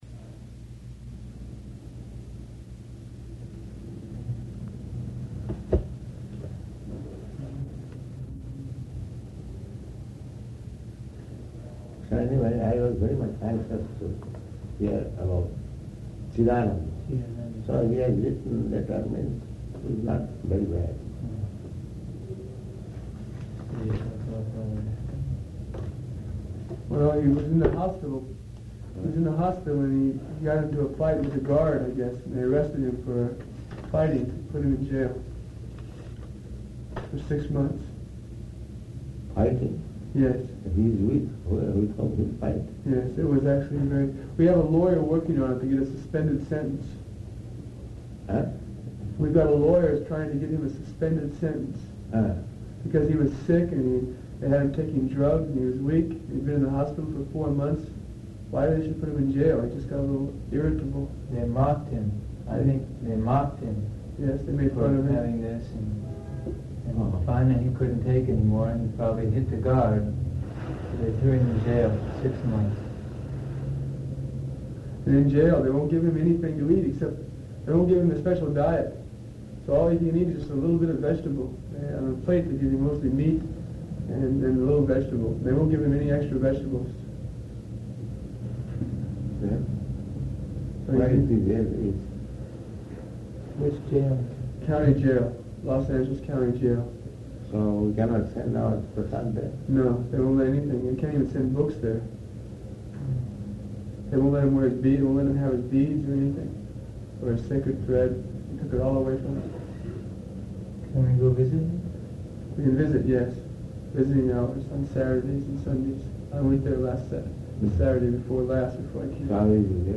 Room Conversation
Location: Los Angeles